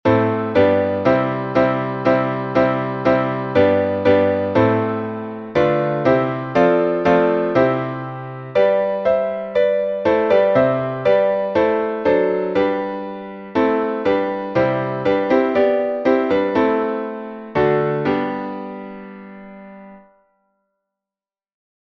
Знаменный распев, глас 1